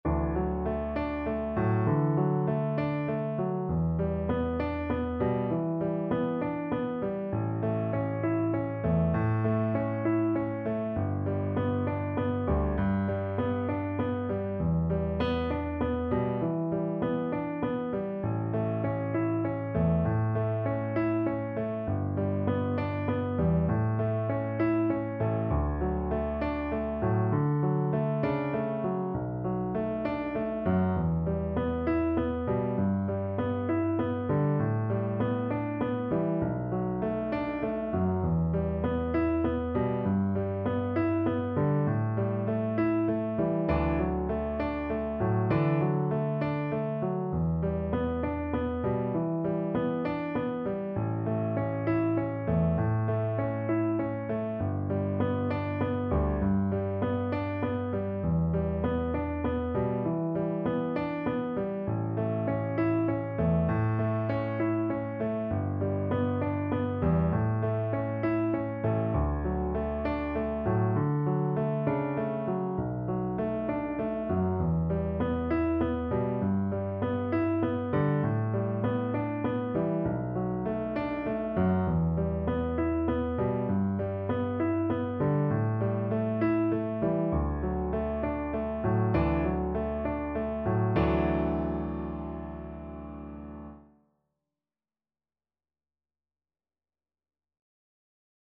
6/8 (View more 6/8 Music)
Classical (View more Classical Viola Music)